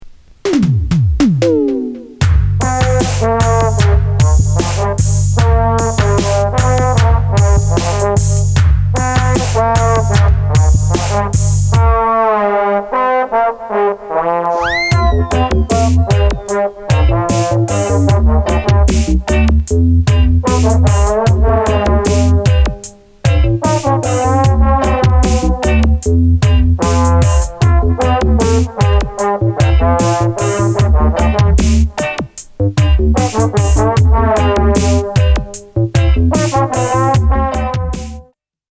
コンピュータライズド・レイト８０'Sな演奏に、
トロンボーン・インスト・レゲー・カバー！！！
何故か哀愁漂う独特のアレンジ。
妙にロウ・テンポでスピンしずらさも愛嬌。